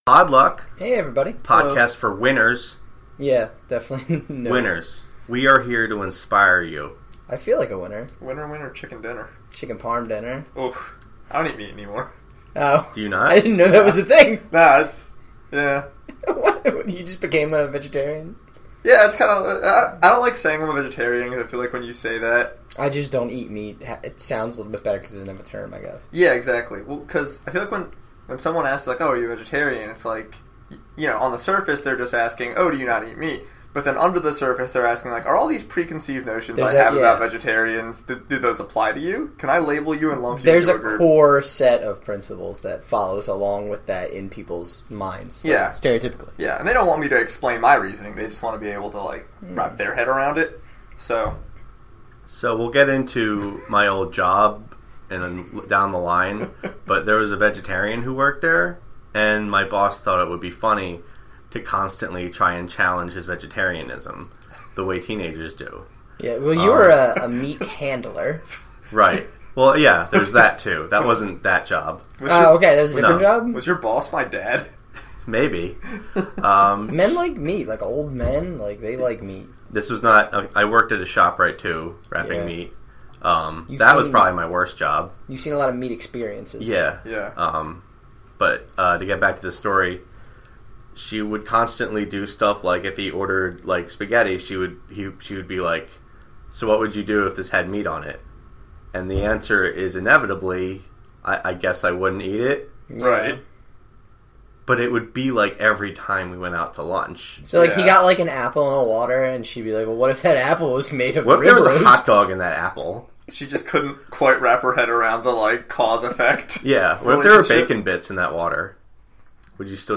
Also, there’s music now!